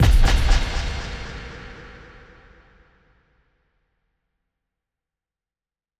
Impact 20.wav